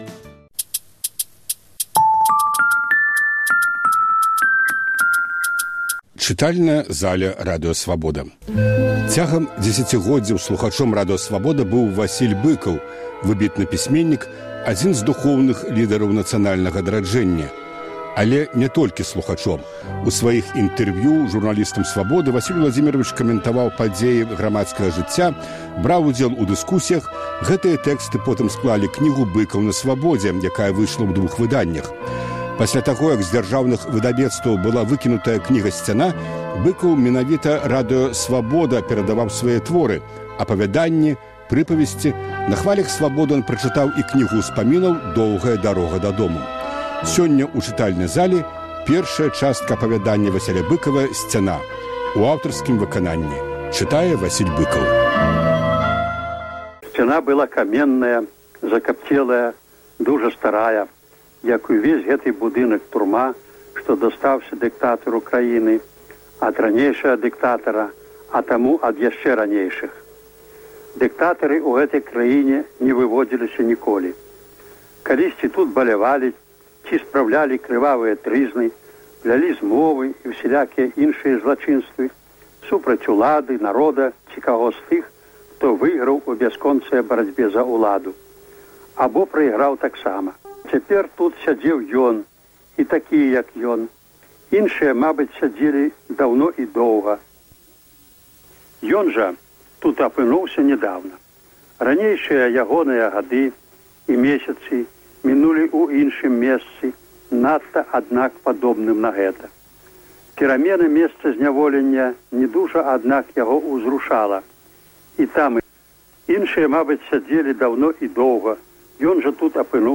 Апавяданьне "Сьцяна" чытае Васіль Быкаў